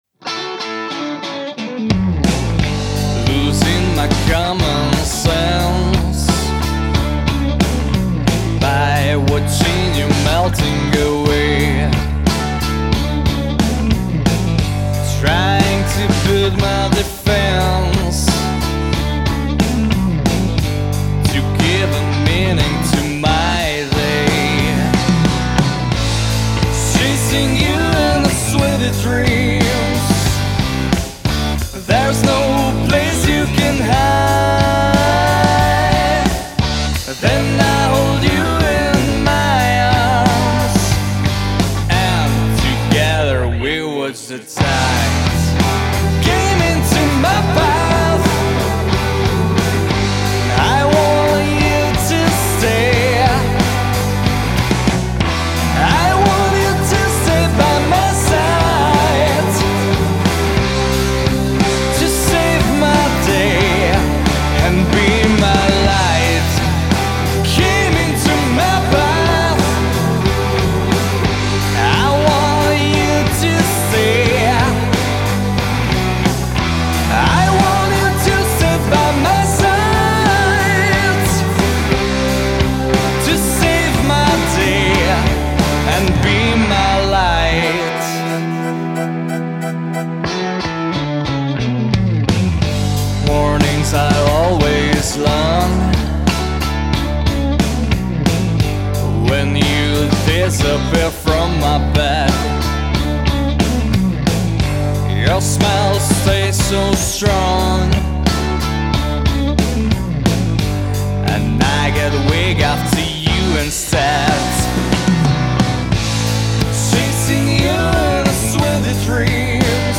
gitary
basgitary, spevy
klávesy
bicie